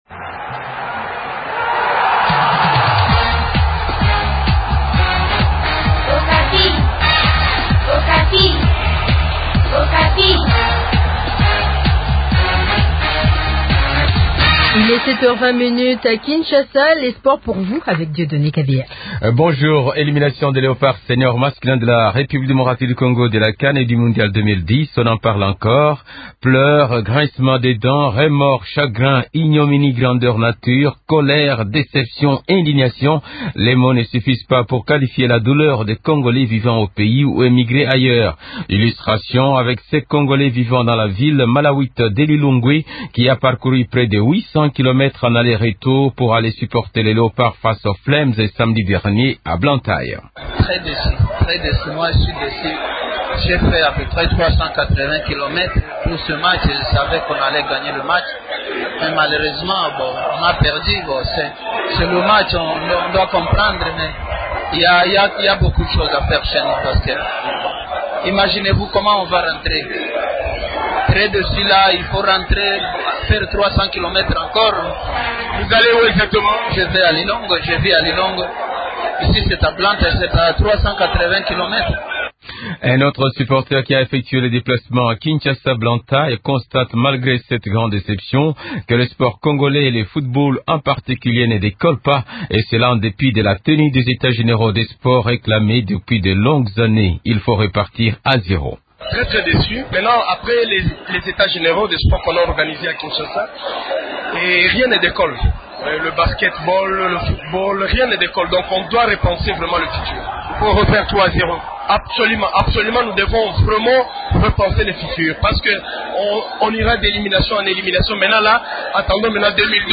Illustration avec ce Congolais vivant dans la ville malawite de Lilongwe qui a parcouru près de 800 kilomètres en aller –retour pour aller supporter les Léopards face aux Flames samedi dernier à Blantyre.